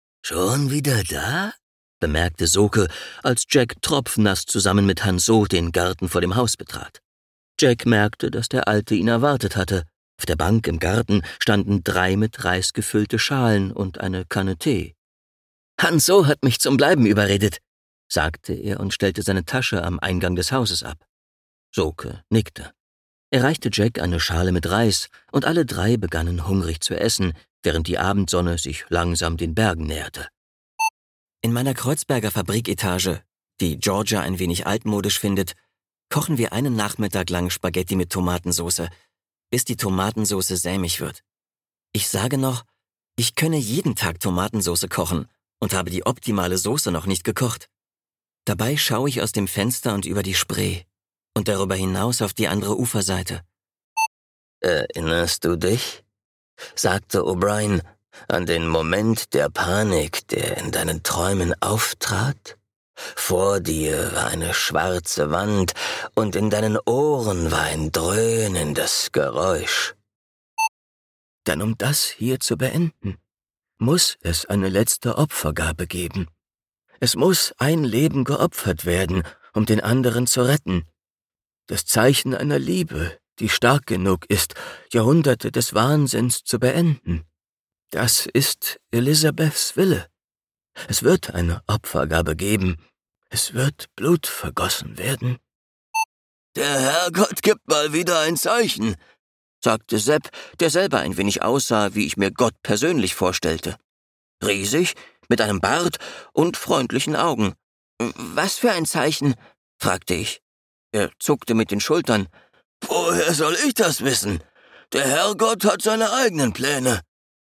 Hörbuchprojekte